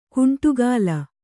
♪ kuṇṭugāla